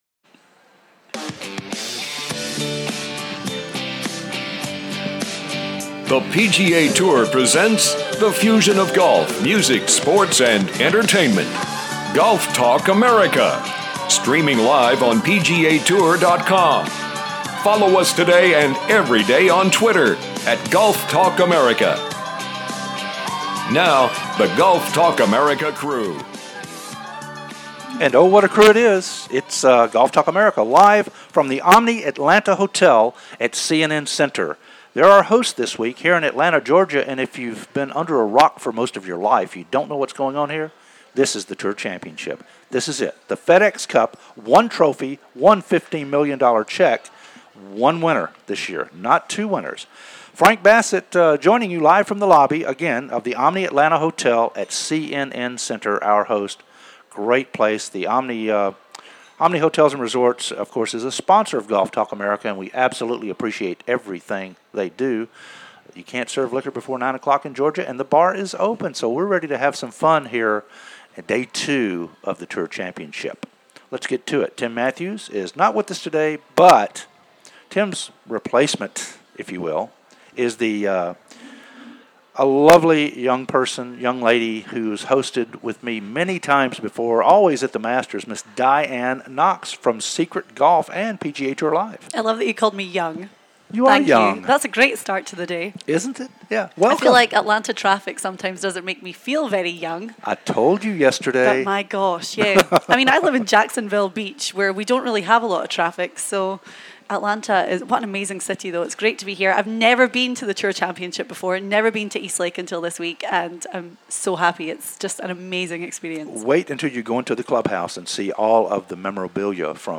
"LIVE" From The Tour Championship & The Omni Hotel Atlanta at CNN Center